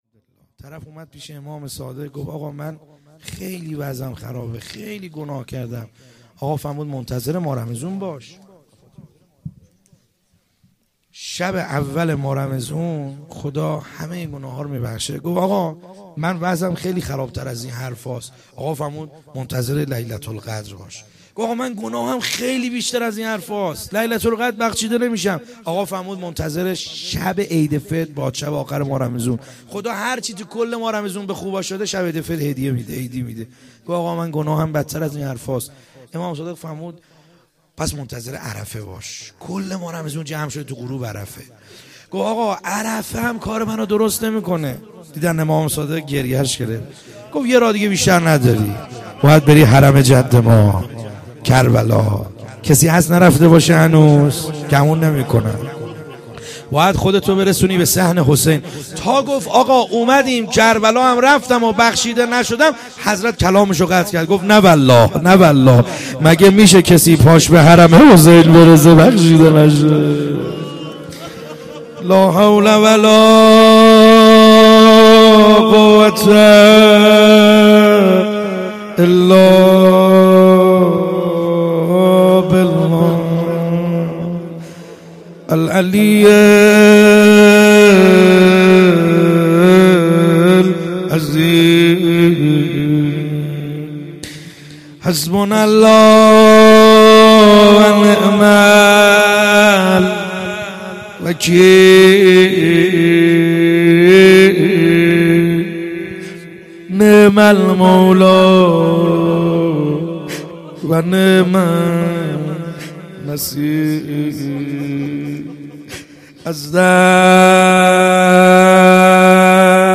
خیمه گاه - بیرق معظم محبین حضرت صاحب الزمان(عج) - مناجات خوانی